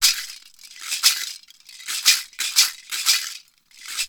PERC 17.AI.wav